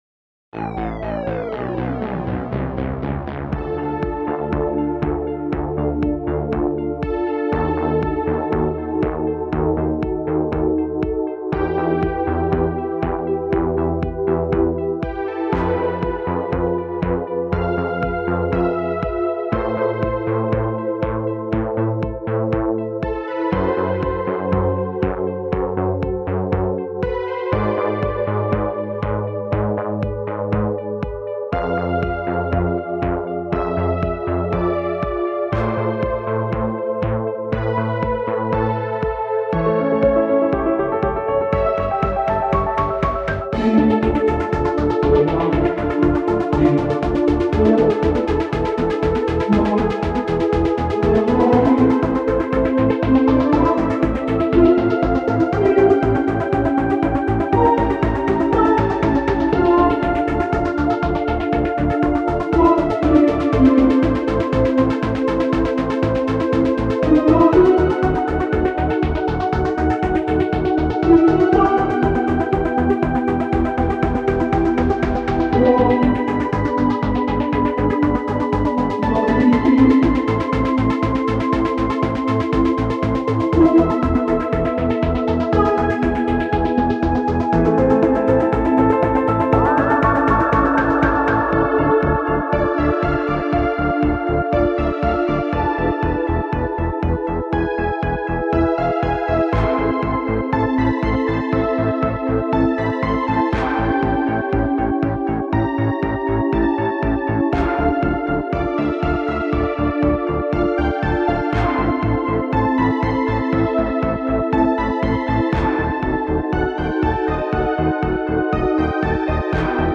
Эпическая музыка
Электронная музыка